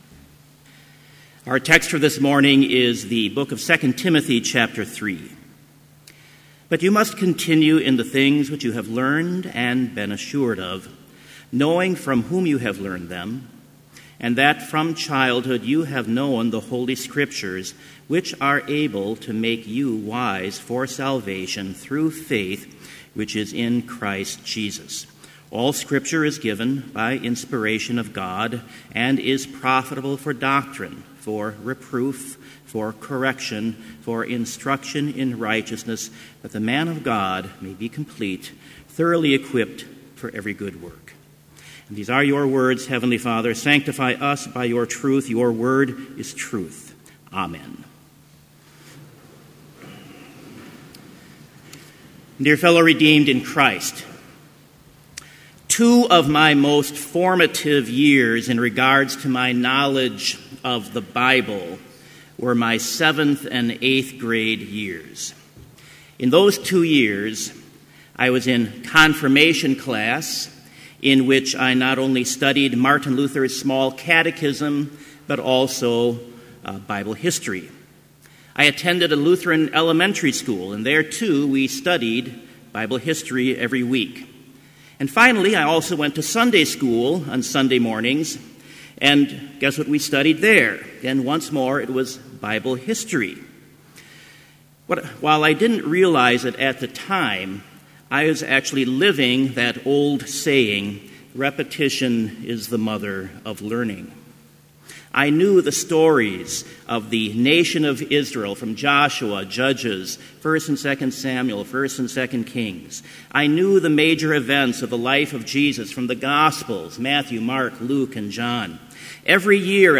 Complete Service
• Prelude
• Hymn 13, Dearest Jesus, Draw Thou Near Me
• Devotion
This Chapel Service was held in Trinity Chapel at Bethany Lutheran College on Tuesday, October 10, 2017, at 10 a.m. Page and hymn numbers are from the Evangelical Lutheran Hymnary.